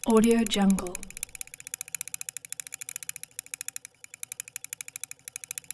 دانلود افکت صدای چرخش فیلم 35 میلی متری
• ایجاد حس نوستالژی و سینمایی: صدای چرخش فیلم 35 میلی متری به پروژه‌های شما حس نوستالژیک و سینمایی می‌بخشد و آن‌ها را جذاب‌تر می‌کند.
16-Bit Stereo, 44.1 kHz